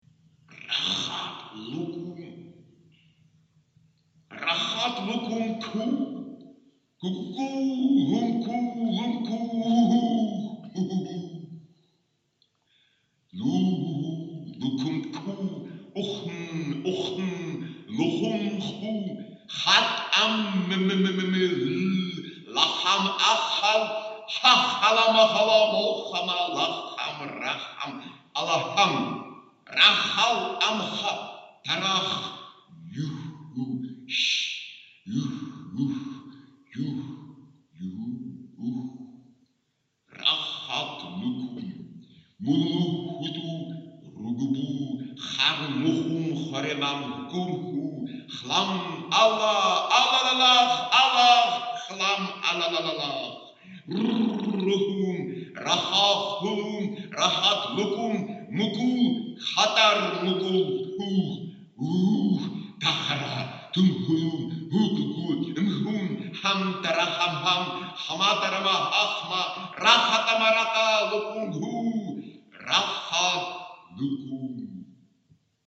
Aufnahmen von der Lesung in Leipzig mit Lautgedichten
Lautgedicht 3